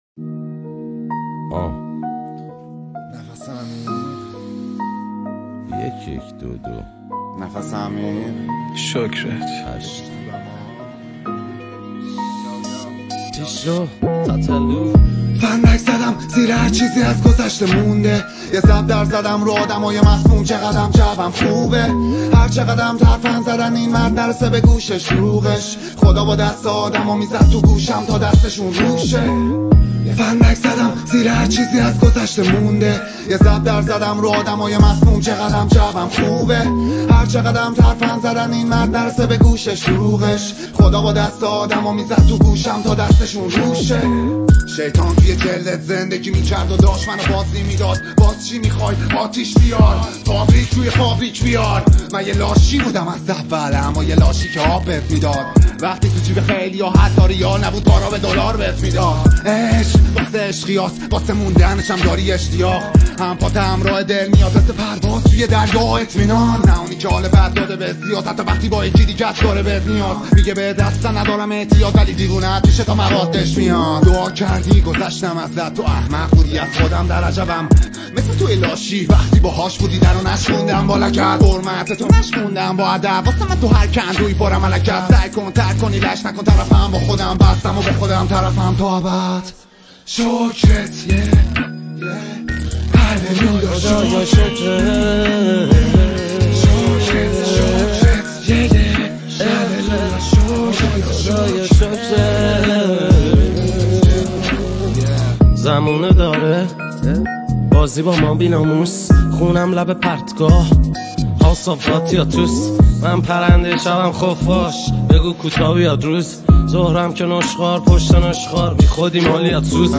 موزیک و اصلاح اهنگ های رپ